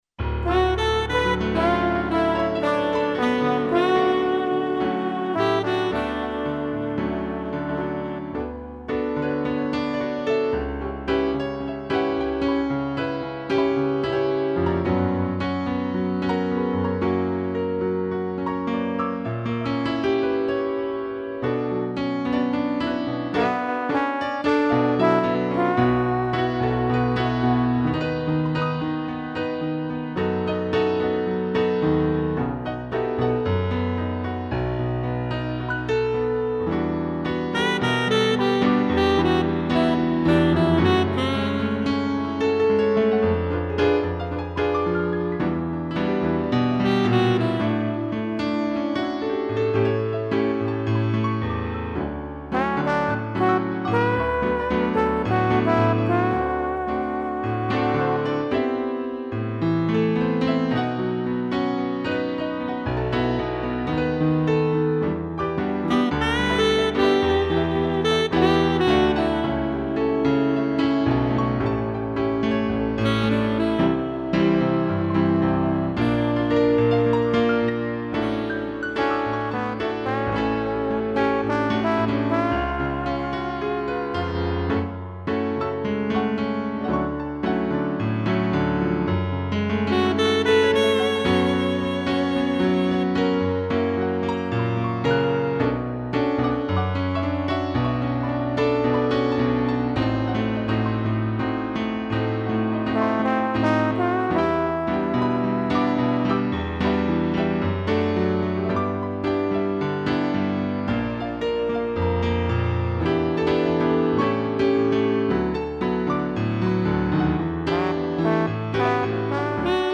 2 pianos, sax e trombone